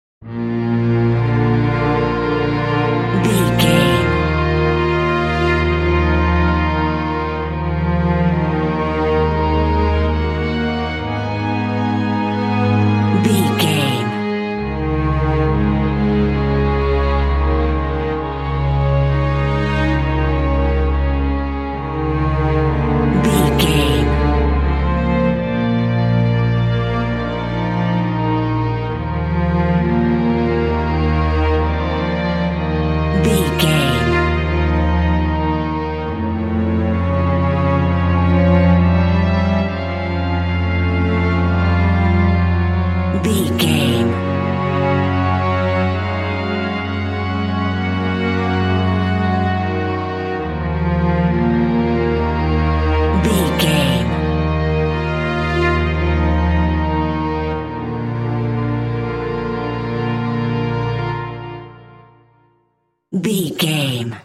Ionian/Major
B♭
dramatic
foreboding
suspense
reflective
strings
cinematic
orchestral
film score